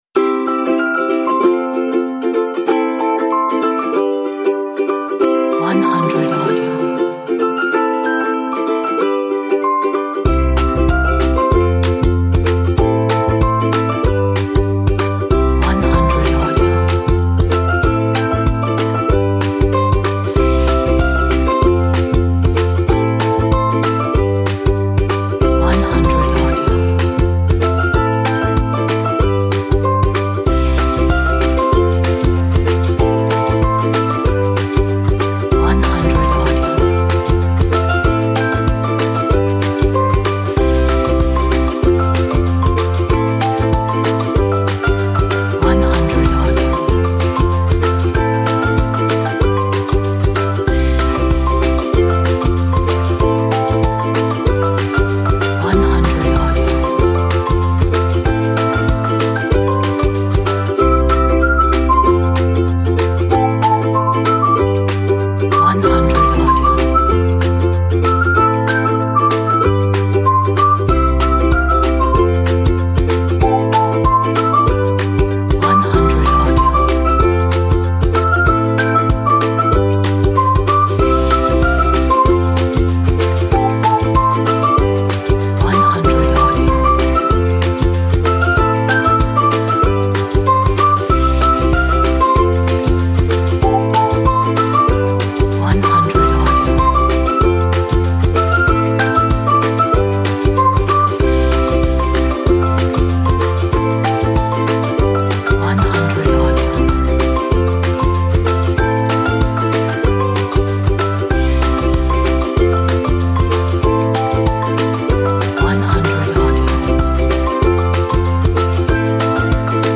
fun and upbeat acoustic track